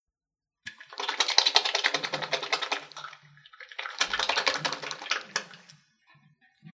Lavarse frotando efusivamente las manos
Grabación sonora del sonido producido por una persona al lavarse las manos con agua y jabón frotándoselas una vez enjabonadas de manera efusiva.
Sonidos: Acciones humanas